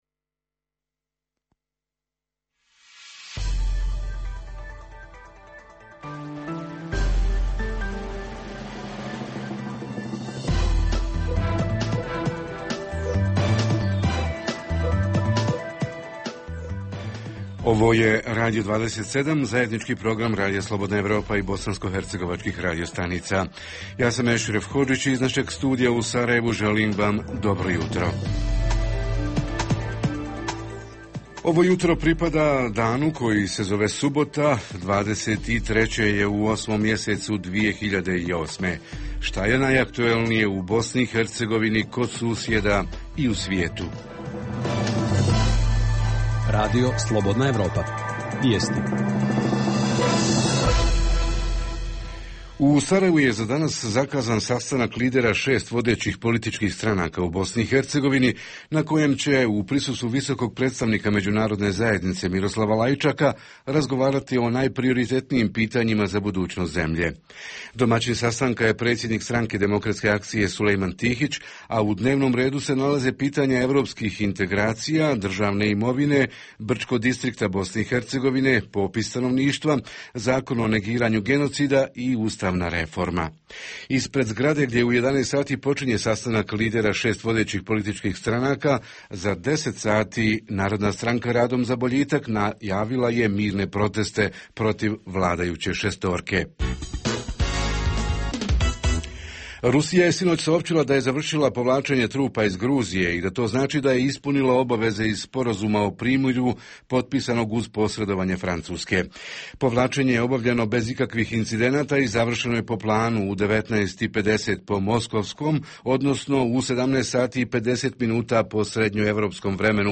U ovom jutarnjem programu govorimo o bosanskohercegovačkom turizmu. Reporteri iz cijele BiH javljaju o najaktuelnijim događajima u njihovim sredinama.
Redovni sadržaji jutarnjeg programa za BiH su i vijesti i muzika.